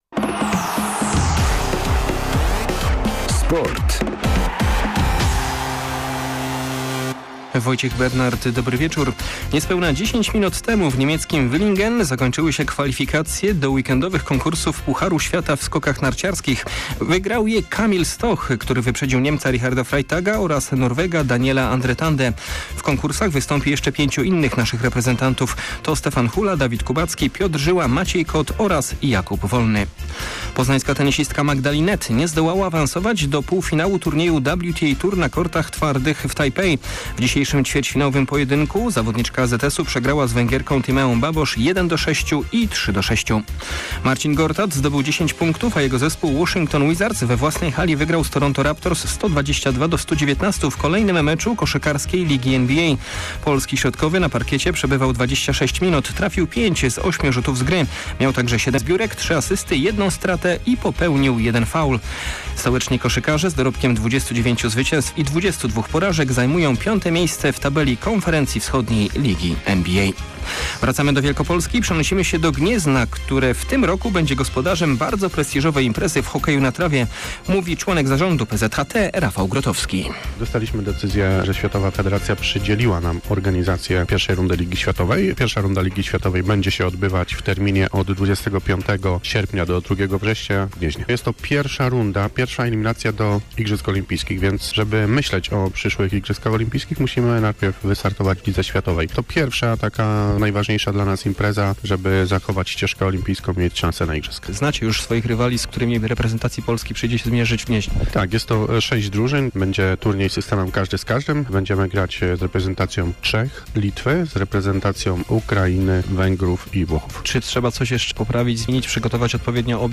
02.02 serwis sportowy godz. 19:05